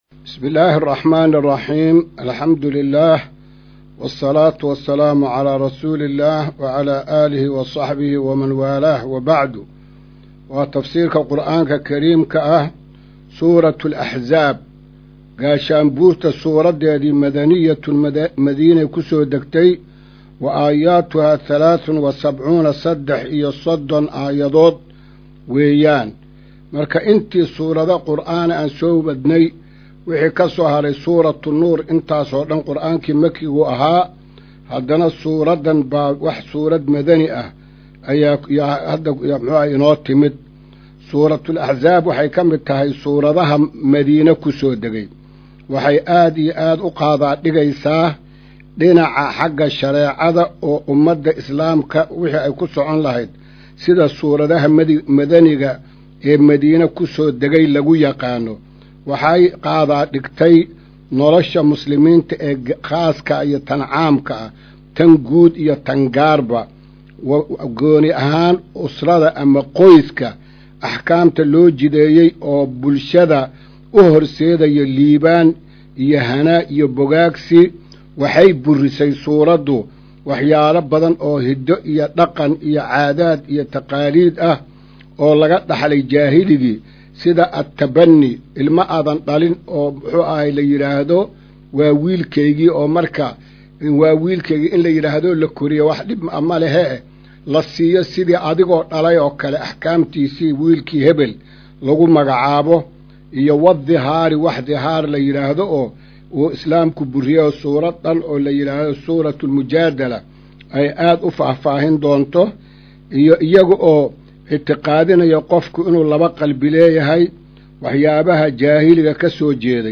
Maqal:- Casharka Tafsiirka Qur’aanka Idaacadda Himilo “Darsiga 196aad”